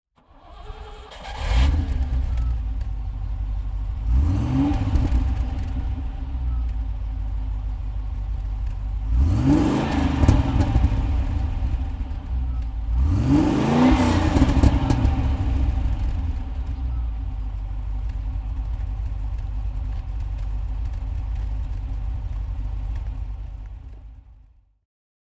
Rover SD1 Vitesse (1985) - Starten und Leerlauf
Rover_SD1_Vitesse_1985.mp3